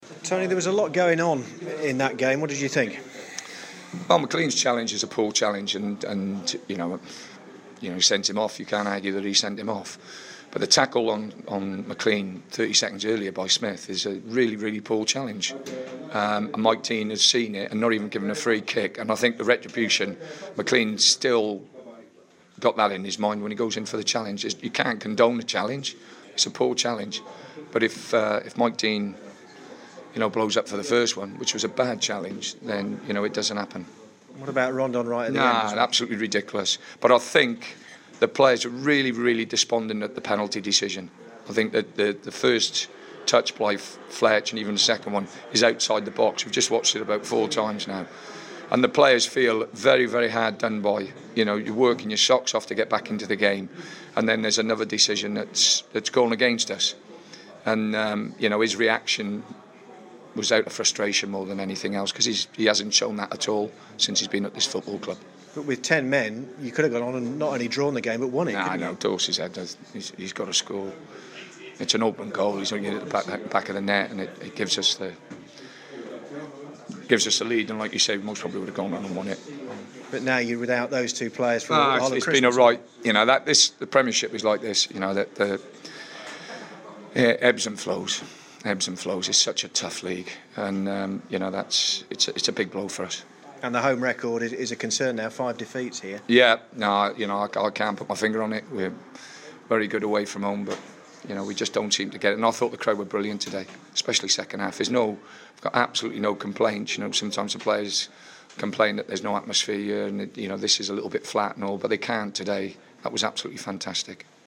Tony Pulis talks to BBC WM about the defeat at home to Bournemouth where James McClean and Salomon Rondon were sent off and the visitors were awarded a penalty.